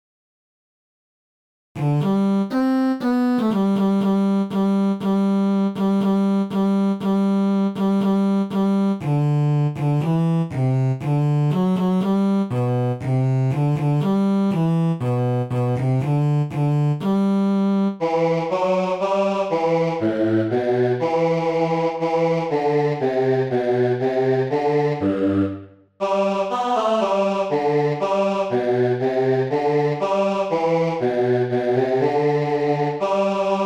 bass
adon_olam_bass.mp3